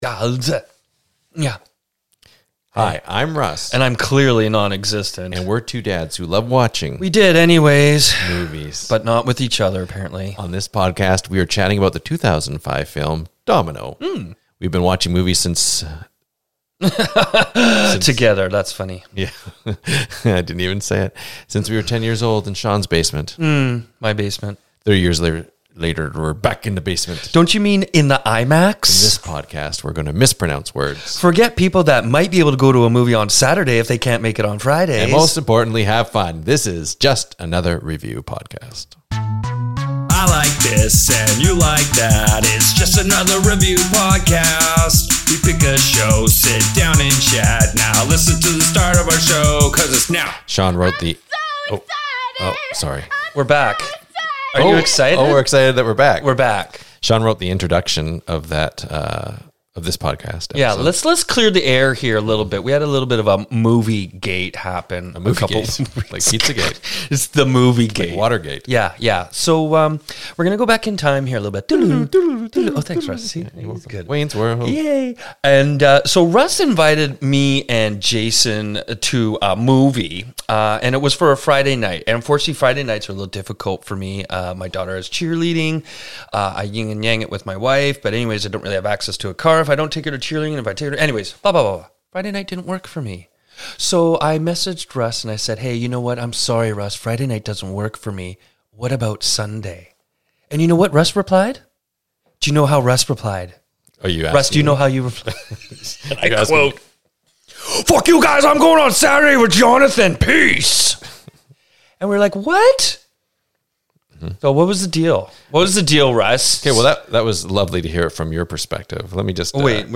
The two miscast Podcasters mispronounce words, forget actors names, and most importantly have fun.